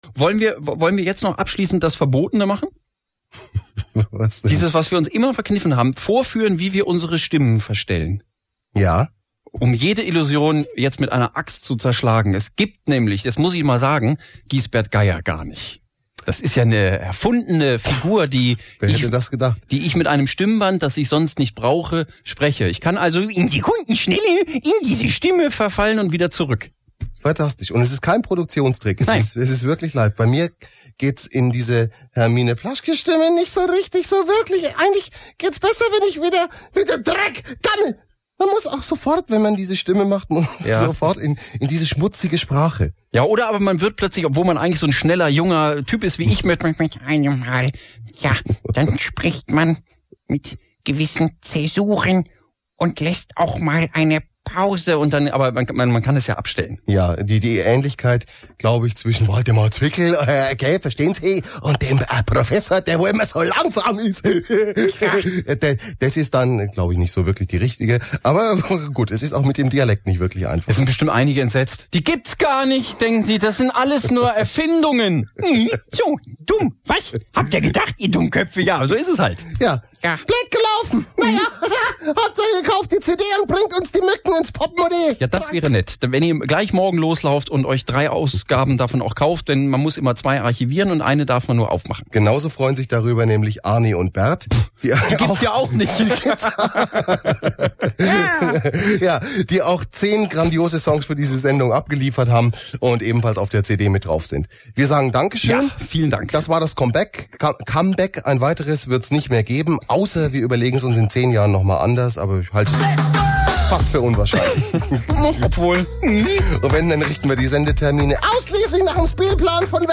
• Radio-Demaskierung in der letzten Sendung am 09.05.2004
machen vor, wie sie ihre Stimmen verstellen